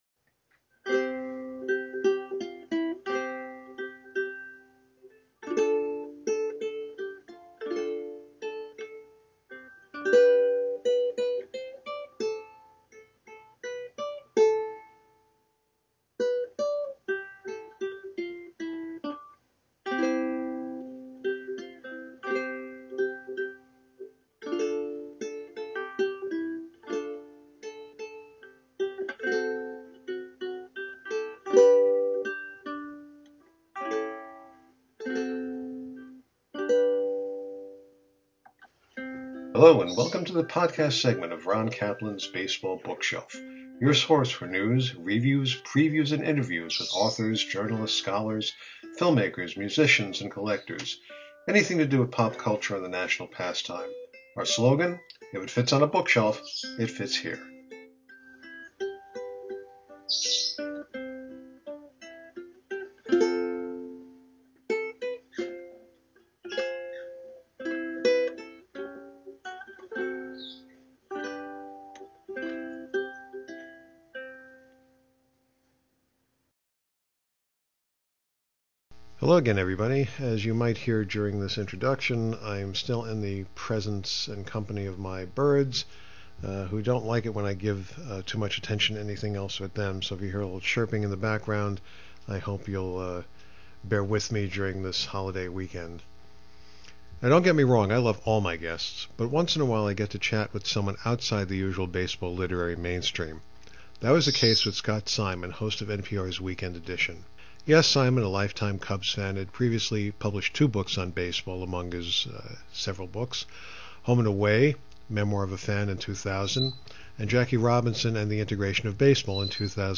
That was the case with Scott Simon, host of NPR’s Weekend Edition.
Simon has a natural storyteller’s voice , but he’s usually telling other people’s stories. In My Cubs , he gets to express what the team has meant to him, his family (which includes former Cubs’ announcer Jack Brickhouse and player/manager “Jolly Cholly” Grimm ), and the extended family of fellow fans through thick and thin over the years.